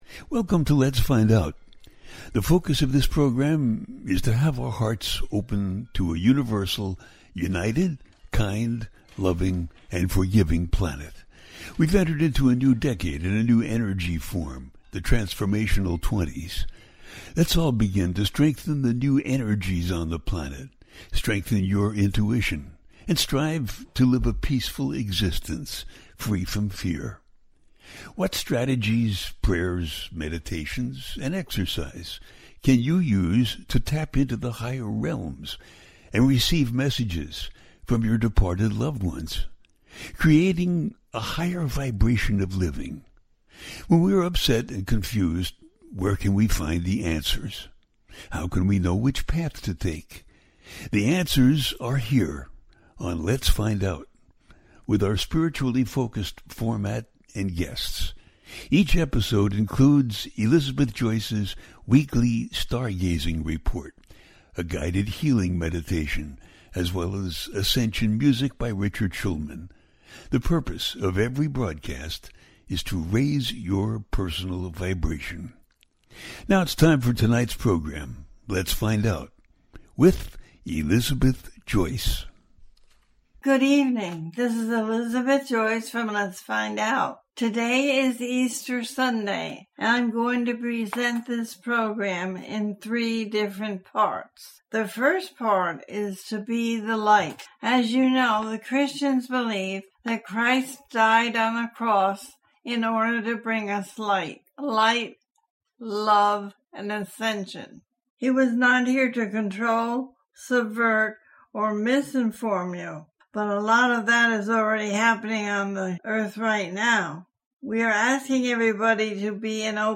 Ascending Into the Light and What is in Store for Early May 2025 - A teaching show
The listener can call in to ask a question on the air.
Each show ends with a guided meditation.